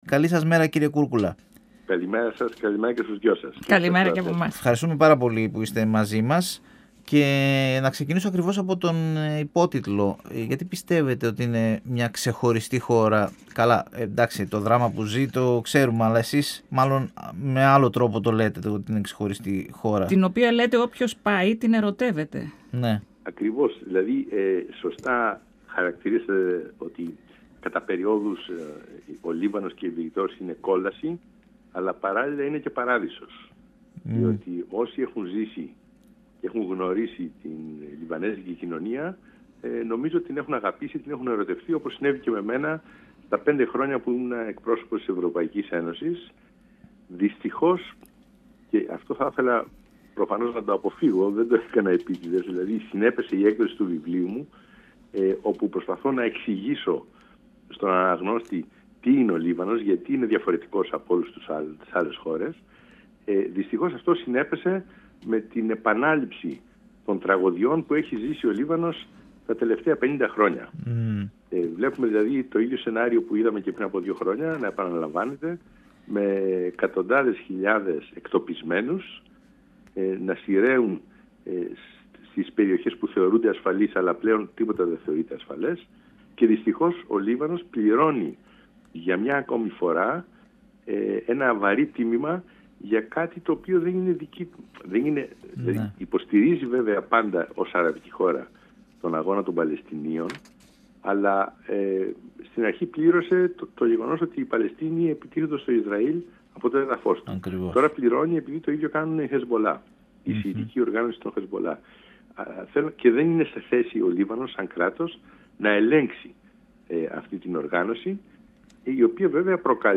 Ο πρώην υφυπουργός Εξωτερικών και πρώην πρέσβης της ΕΕ στον Λίβανο, Δημήτρης Κούρκουλας, μίλησε στην εκπομπή «Τα Πιο Ωραία Πρωινά», με αφορμή το βιβλίο του “Λίβανος” που δυστυχώς συνέπεσε με τα όσα συμβαίνουν σήμερα στην περιοχή. Ο Δημήτρης Κούρκουλας περιέγραψε την προσωπική του εμπειρία, ενώ εξήγησε το πώς η πολιτική διάρθρωση της πολύπαθης, αλλά «υπέροχης» χώρας επηρεάζεται από τη θεσμοθετημένη ισορροπία των πολλών διαφορετικών θρησκειών και της ύπαρξης της Χεζμπολάχ. Εξέφρασε, ωστόσο, την αισιοδοξία του για το μέλλον του Λιβάνου, που είναι «εφτάψυχο» και έχει περάσει πολλές και βαθιές κρίσεις. 102FM Τα Πιο Ωραια Πρωινα Συνεντεύξεις ΕΡΤ3